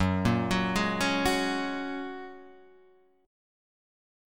F#add9 chord